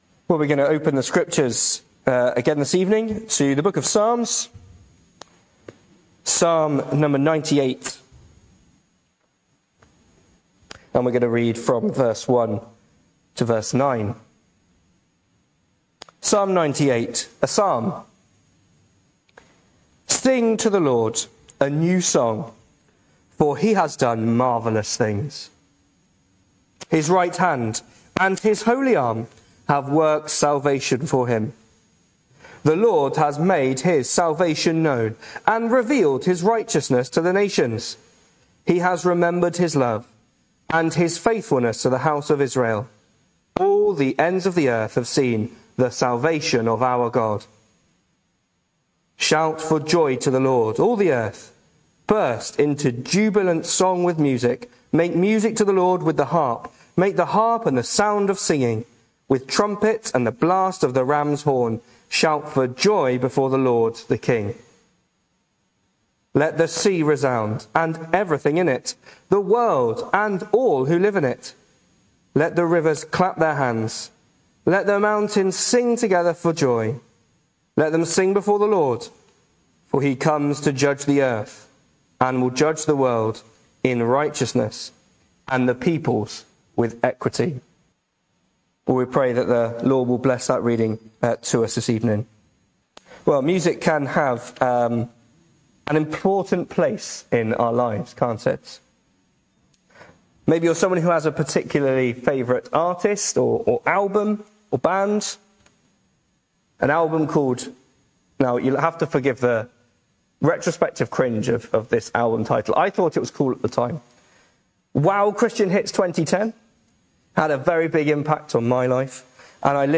Hello and welcome to Bethel Evangelical Church in Gorseinon and thank you for checking out this weeks sermon recordings.
The 17th of August saw us hold our evening service from the building, with a livestream available via Facebook.